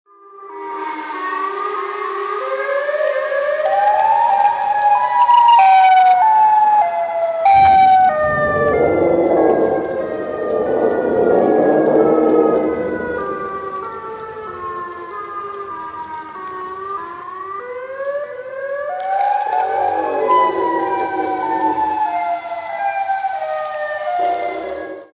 Download Siren Head sound effect for free.